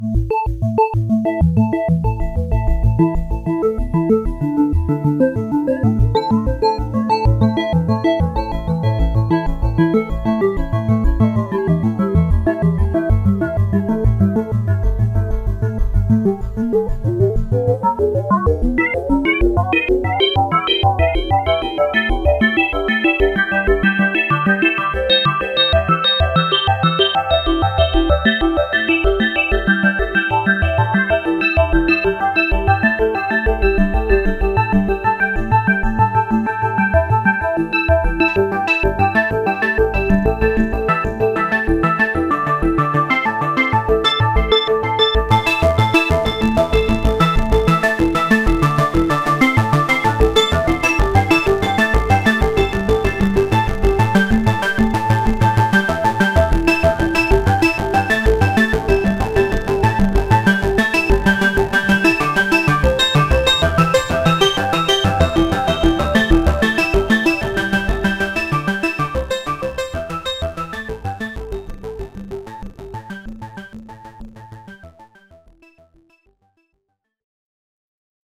FM sounds
A demonstration of the Frequency Modulation feature. Each oscillator can act as modulator and/or carrier. From time to time the pitch is slowly changed while the tune is playing to demonstrate different FM timbres.
[2] external effects have been added
swinsid_fm_seq.mp3